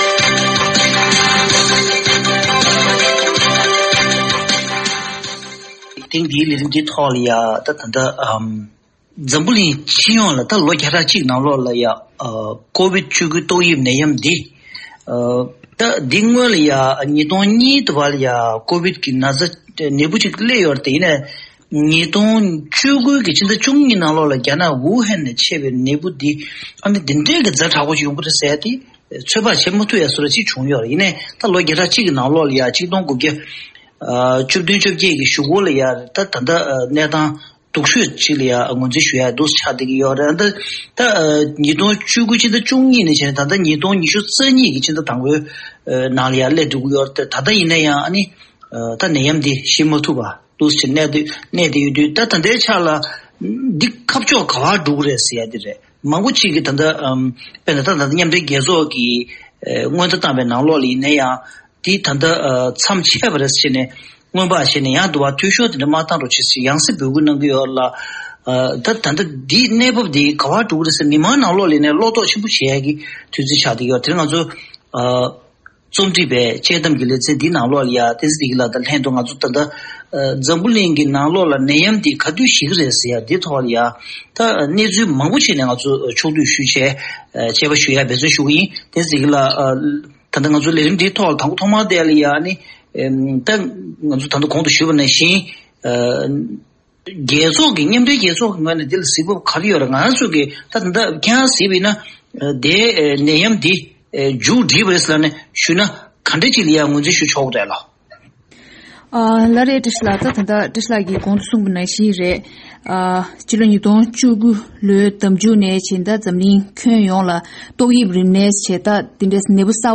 དཔྱད་གླེང་གནང་བ།